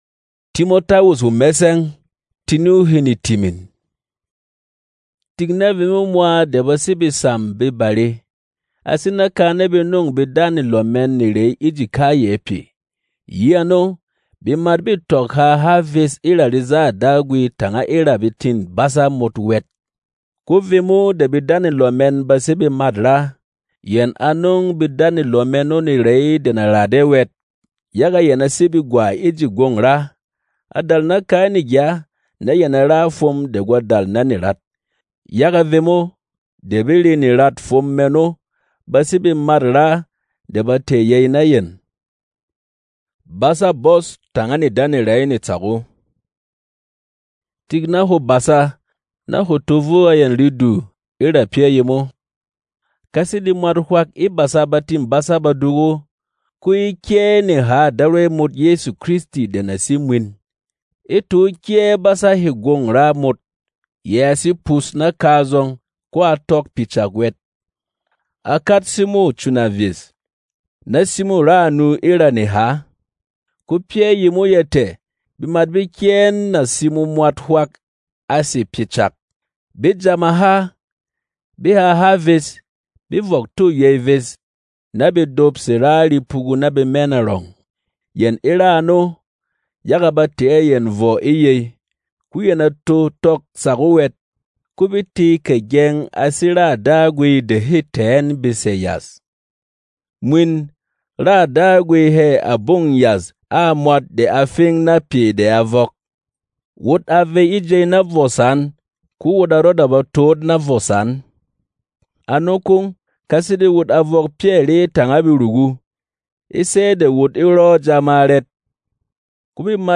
Non-Drama